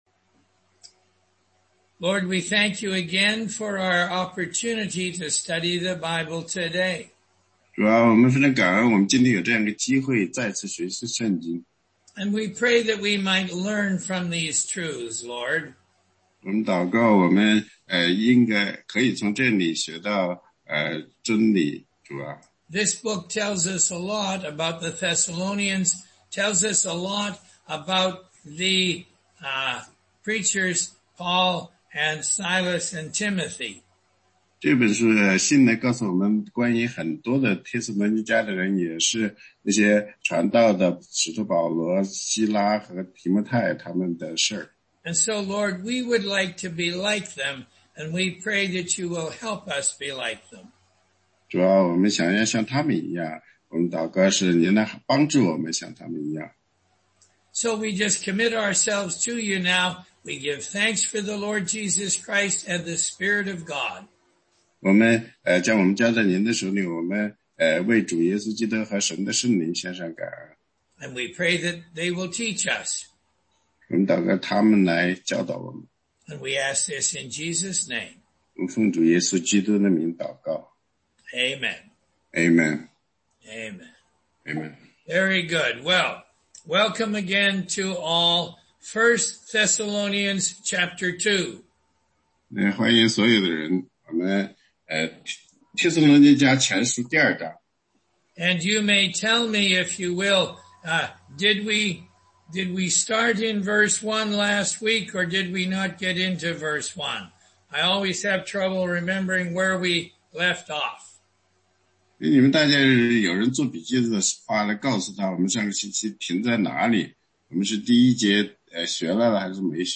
16街讲道录音 - 帖前2章1-14节
答疑课程